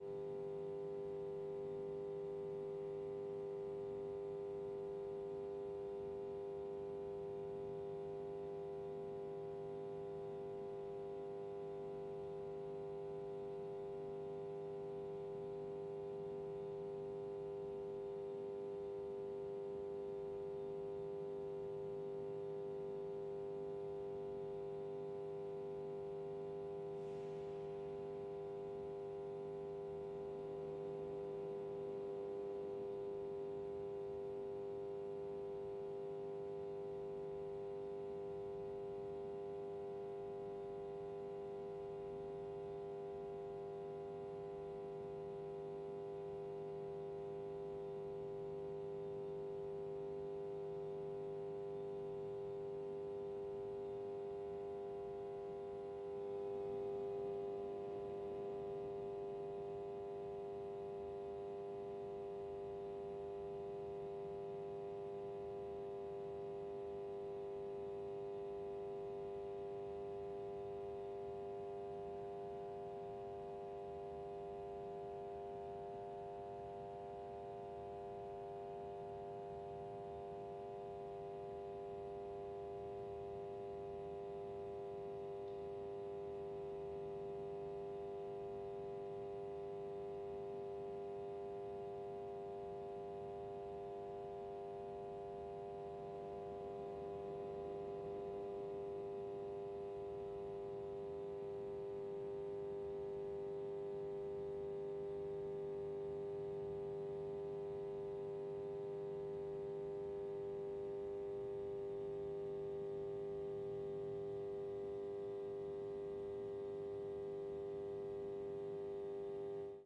室内装饰 " 楼梯间的声音
描述：男子和女子在高层公寓的楼梯间里交谈。门开了又关，
标签： 声音 楼梯间 室内
声道立体声